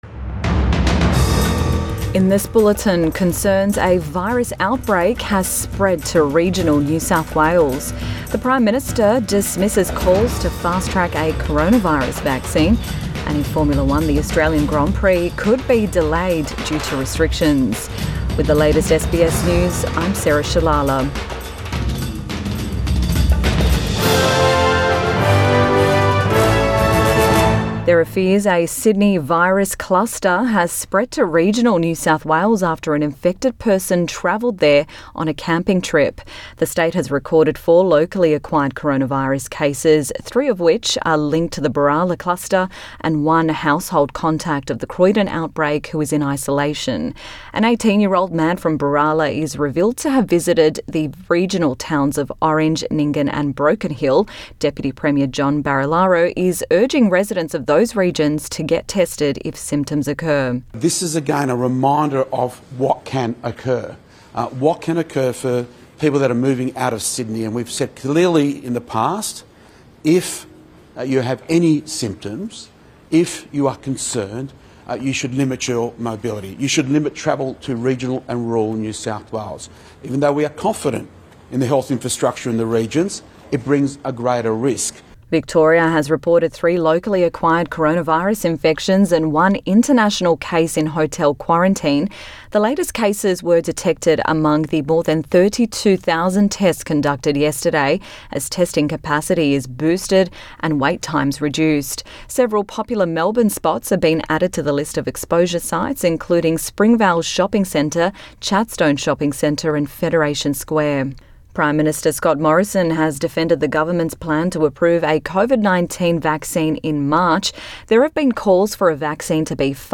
Midday Bulletin 5 January 2021